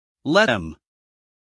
let-them-us-male.mp3